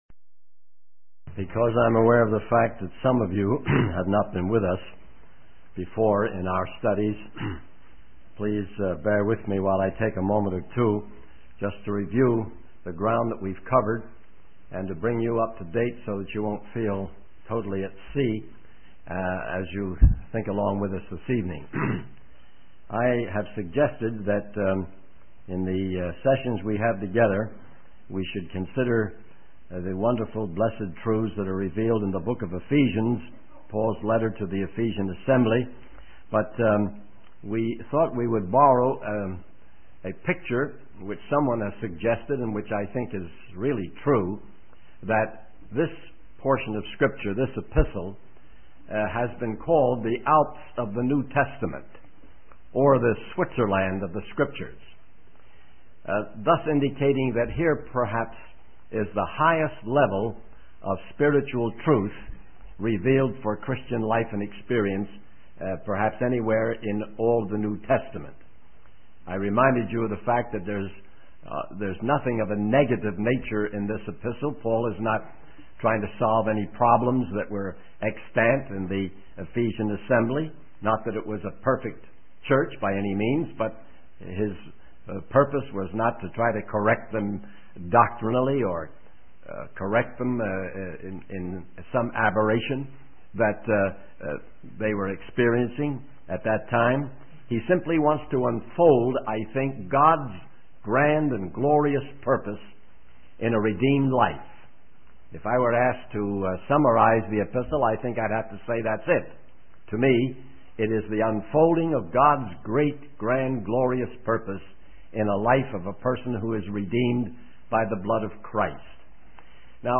In this sermon, the speaker emphasizes the importance of understanding the purpose and message of the epistle. They suggest seeking the highest point of truth in each chapter, referring to it as the 'mountain peak truth.' The sermon also highlights the need for believers to actively pursue God's will and to recognize the personal and parental nature of God's plan.